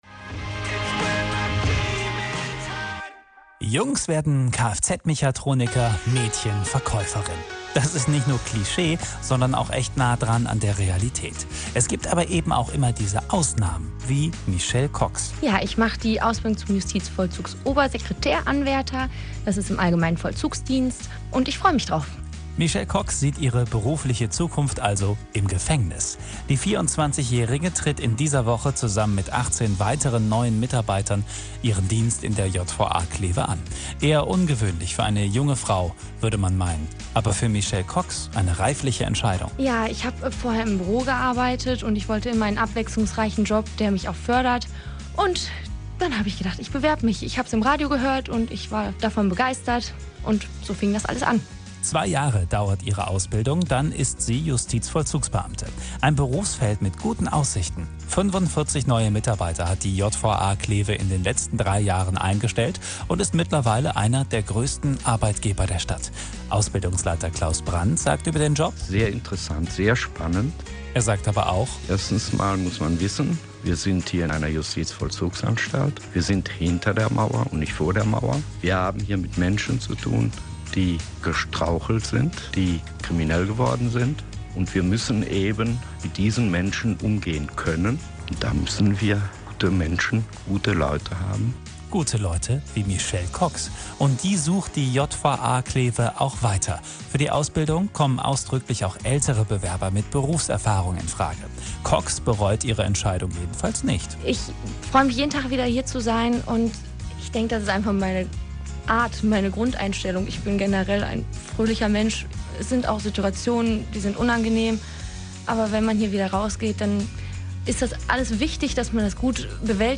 Anzeige play_circle play_circle Radiobeitrag vom 03.07.2019 download play_circle Abspielen download Anzeige